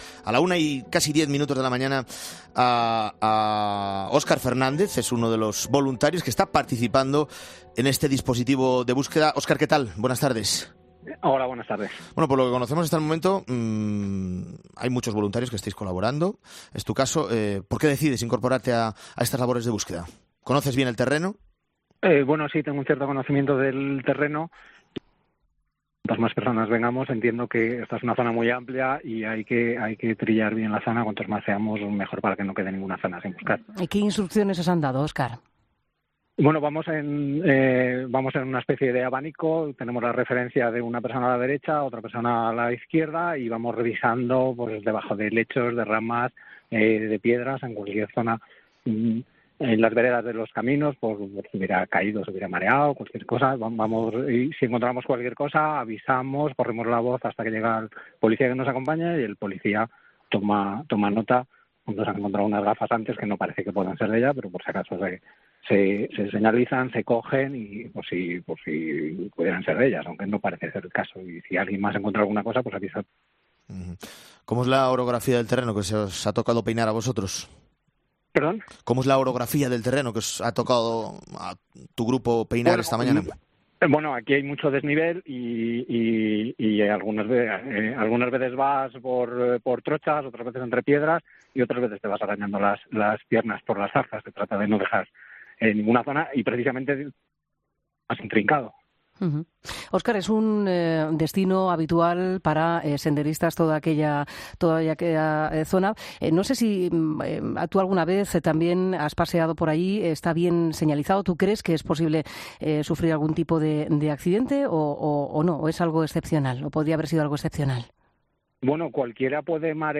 voluntario que participa en la búsqueda